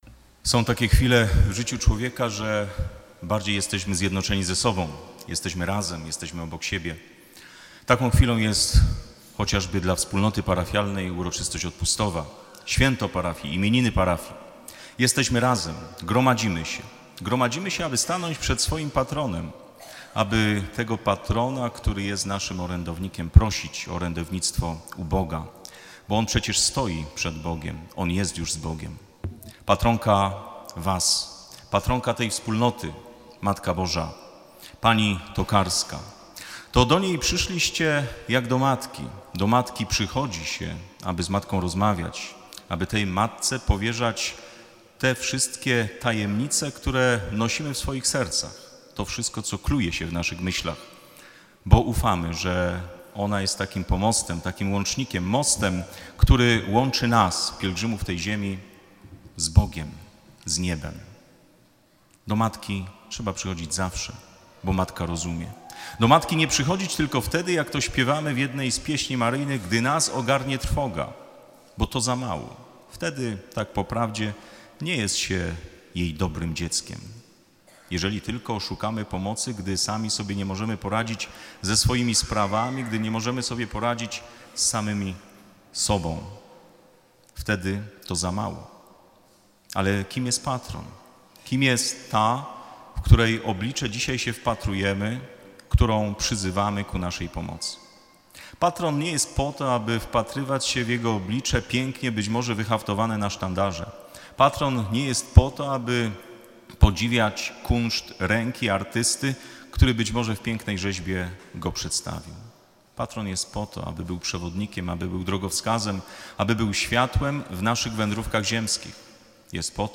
kazanie-odpustowe-tokarnia.mp3